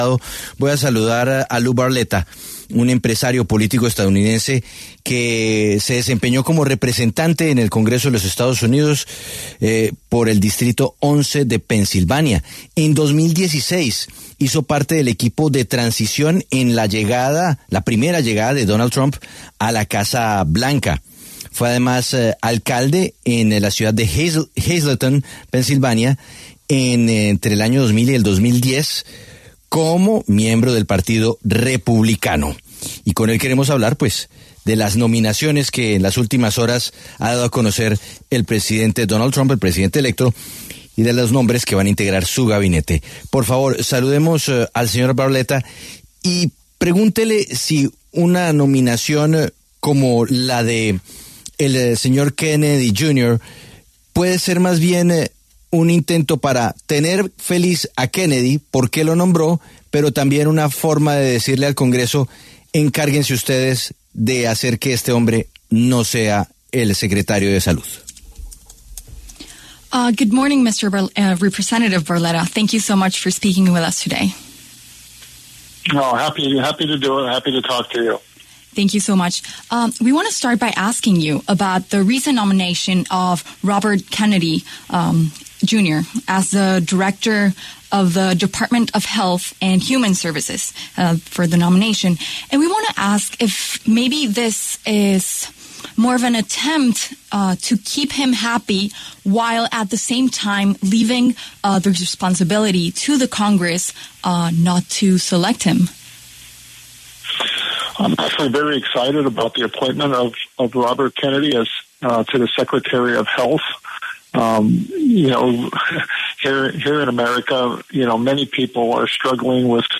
Lou Barletta, excongresista republicano, conversó con La W sobre los controversiales nombramientos de Donald Trump en su gabinete a la Presidencia.